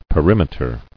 [pe·rim·e·ter]